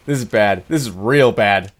Play Jeers 1 - SoundBoardGuy
Play, download and share jeers 1 original sound button!!!!
scout_jeers02.mp3